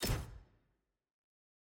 sfx-jfe-ui-generic-click.ogg